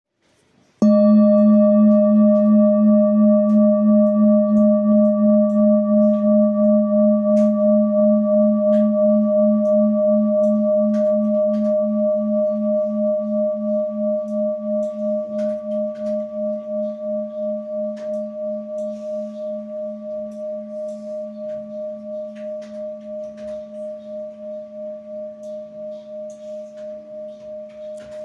Handmade Singing Bowls-30398
Singing Bowl, Buddhist Hand Beaten, with Fine Etching Carving, Select Accessories
Material Seven Bronze Metal